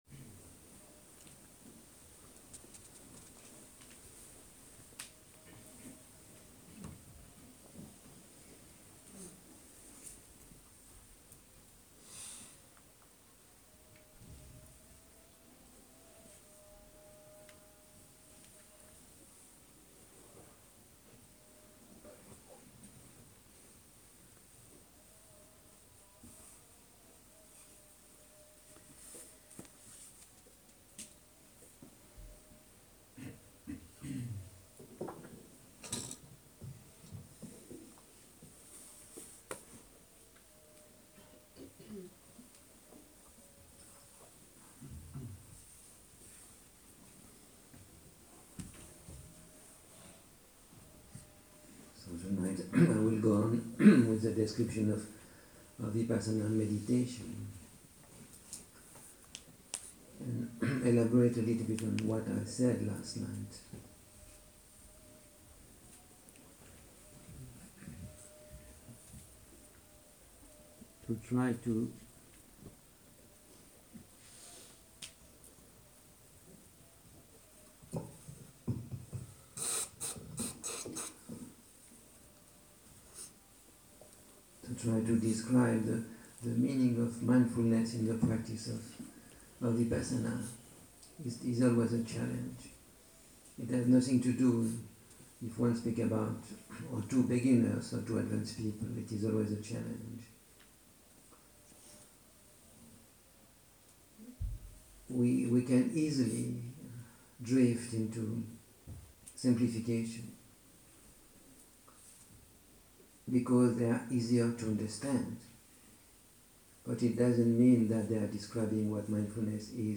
שיחות דהרמה